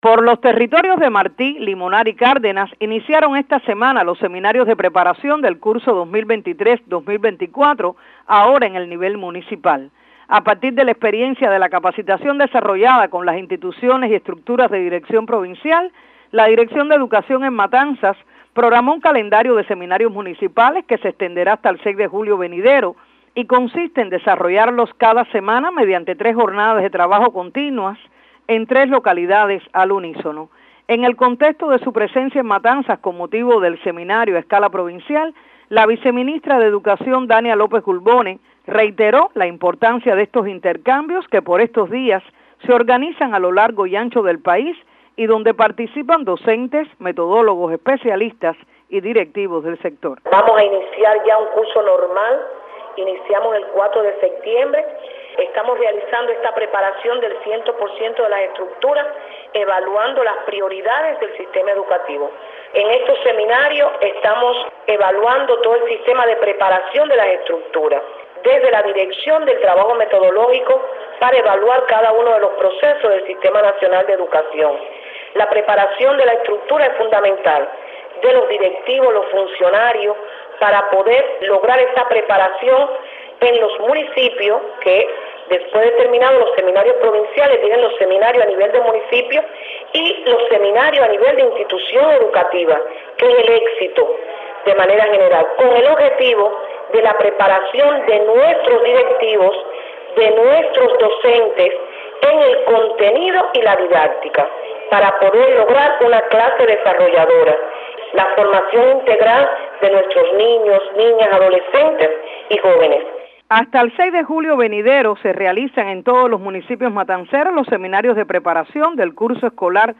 Diversos y esenciales temas para el sector son abordados en estos encuentros, que tienen lugar por estos días a lo largo y ancho del país, según precisó a Radio 26 la viceministra de Educación, Dania López Gulbone, en ocasión de su visita a Matanzas con motivo del Seminario provincial.